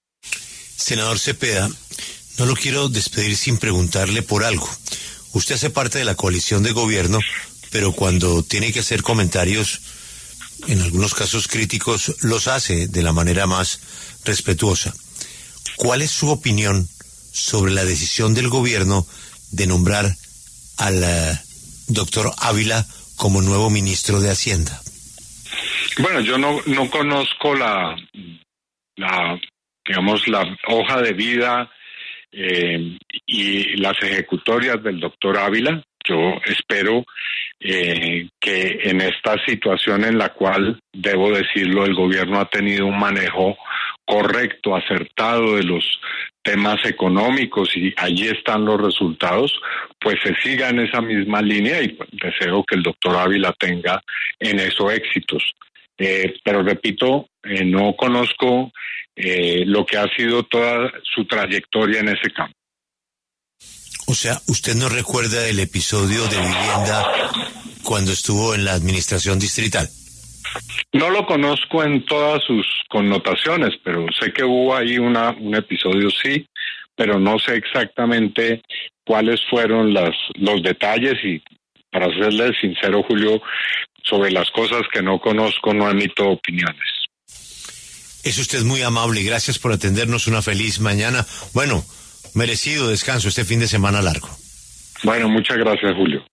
“Deseo que Ávila tenga éxitos”, dijo el senador Iván Cepeda.